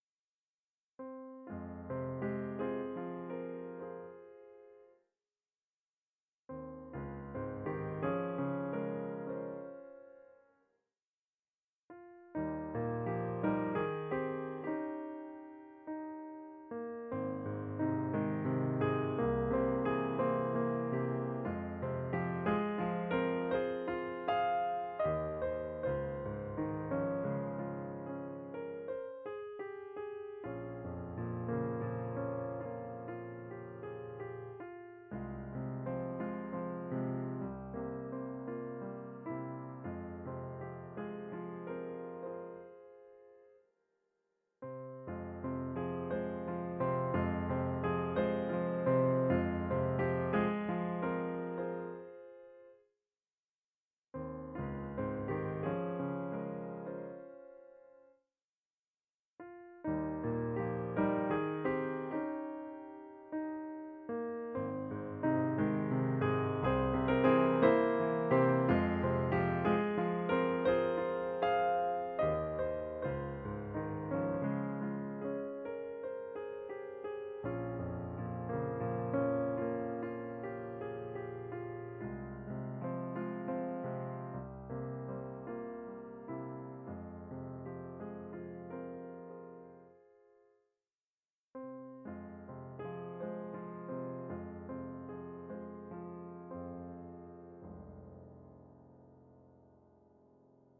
I imagined this to be their traditional funeral procession song.